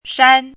chinese-voice - 汉字语音库
shan1.mp3